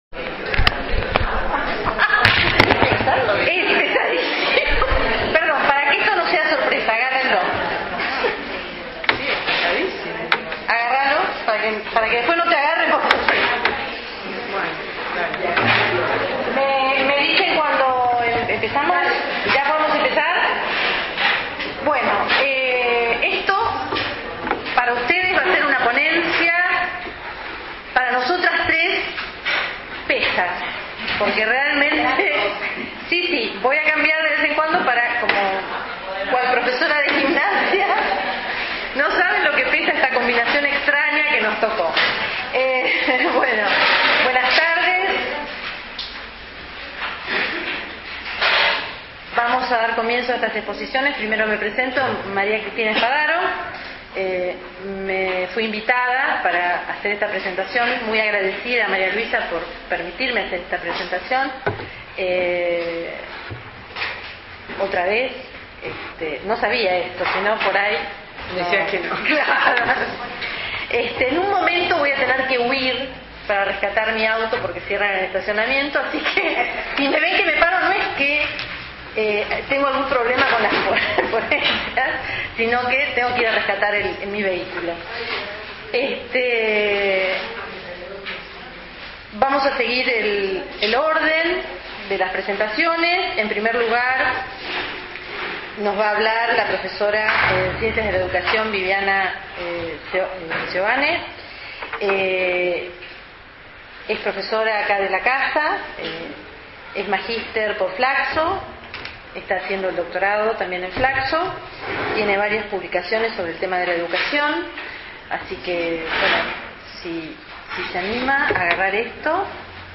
Panel Universidad y la perspectiva de género — Jornadas del CINIG
Panel Universidad y la perspectiva de género